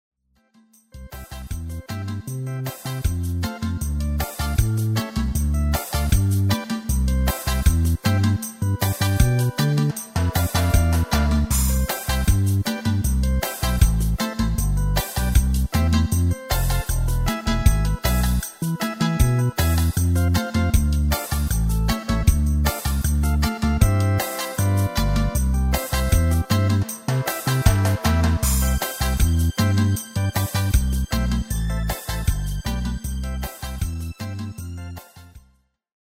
Demo/Koop midifile
Genre: Pop & Rock Internationaal
- Géén vocal harmony tracks